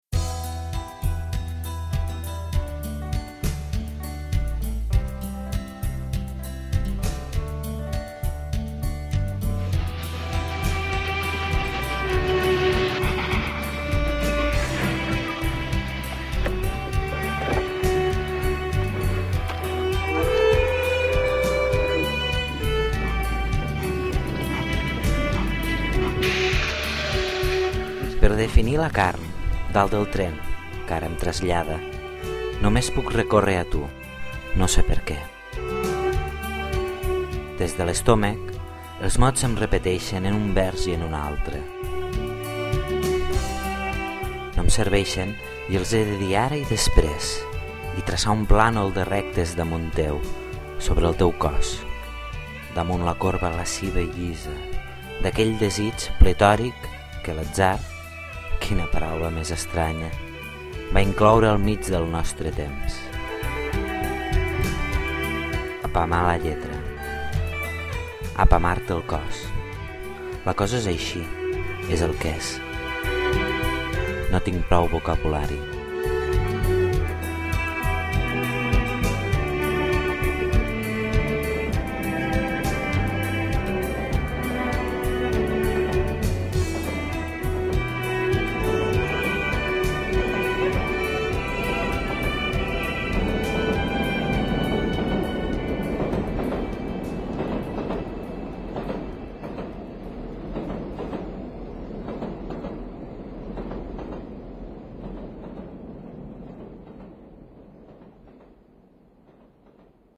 Quin detall el tren al final.
tren al final, i xiulet de tren al principi! quina feinada anara buscant sorolls de trens…